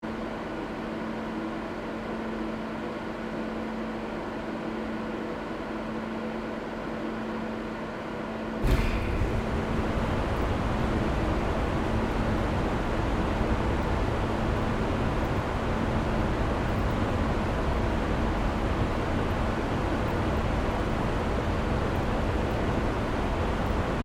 Soundscape: La Silla ESO 3.6-metre-telescope cooling system on
The ventilation system inside the ESO 3.6-metre telescope dome (La Silla Obserbatory) is turned on by a telescope and instrument operator (TIO) during daytime calibrations.
Soundscape Stereo (wav)